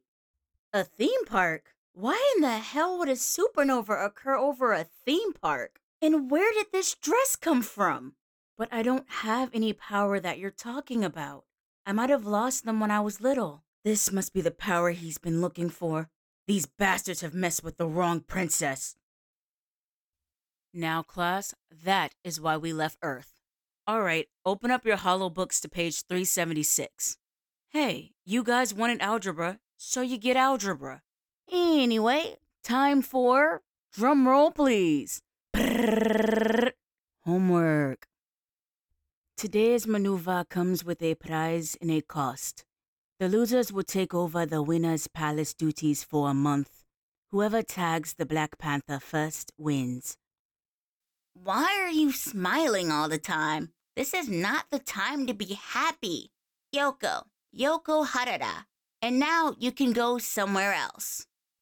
Animation/Video Game Reel
Broadcast-ready home studio.
Character Reel.mp3